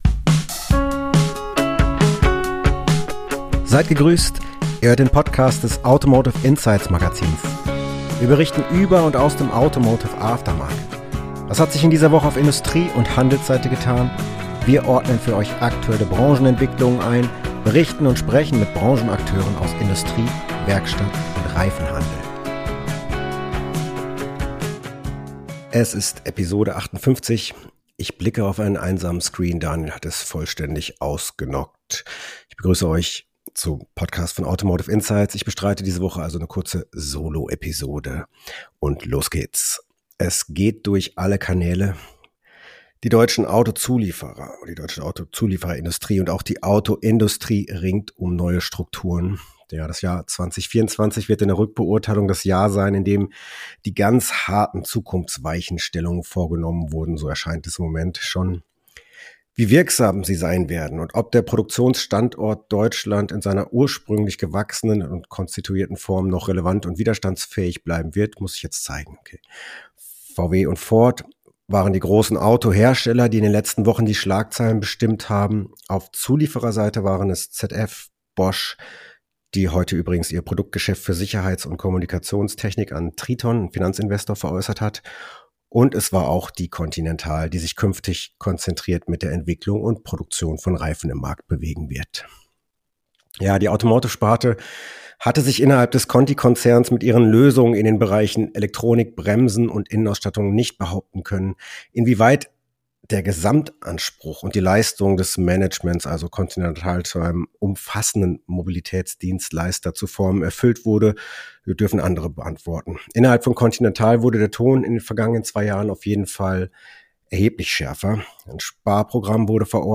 Folge #58 ist eine Solo-Episode.